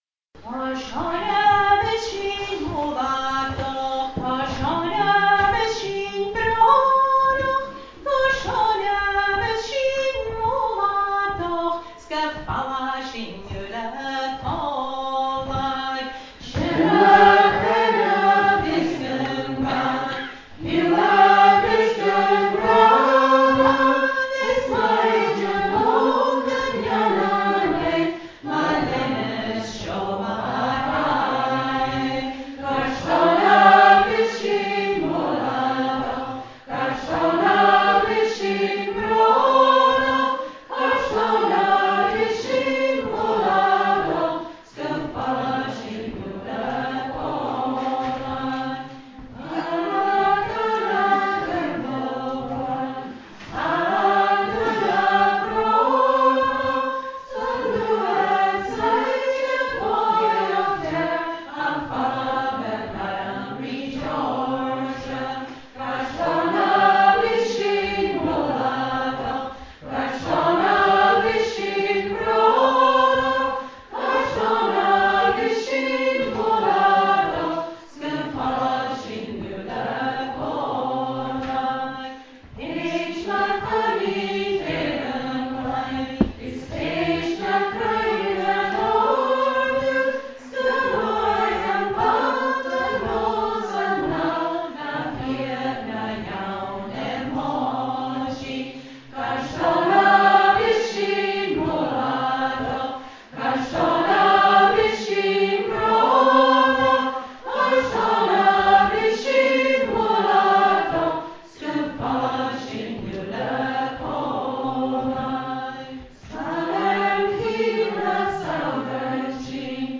Here are recordings from the cèilidh on Thursday night when we sang a some of the songs we learnt: